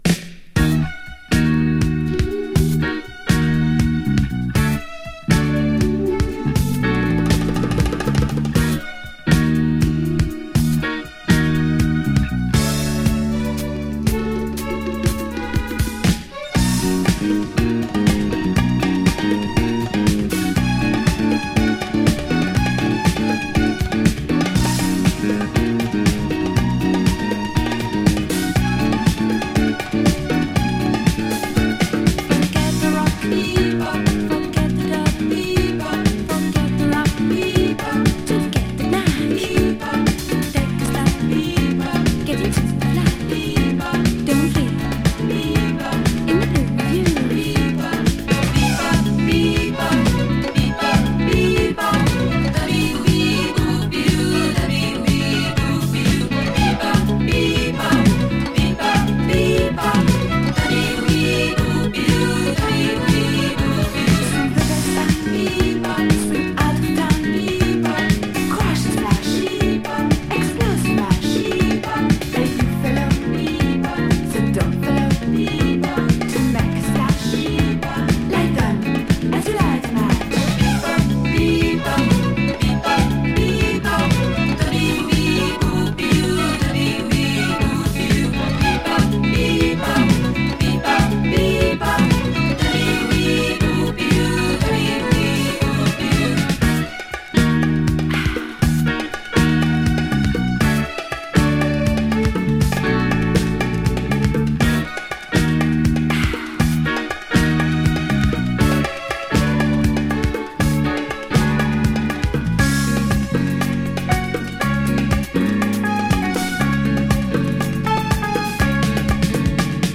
French New Wave Disco!
funcaratina new wave disco
【NEW WAVE】【FRANCE】